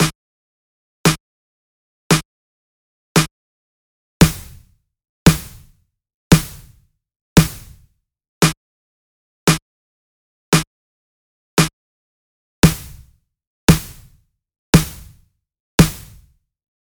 Один из тысячи способов, сформировать звук snare.
Нойс животворящий, и пару плагинов. Вложения Snare_test_001.mp3 Snare_test_001.mp3 660,2 KB · Просмотры: 339